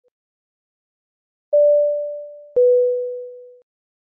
Seatbelt Chime Sound Effect
Seatbelt-Chime-Sound-Effect.mp3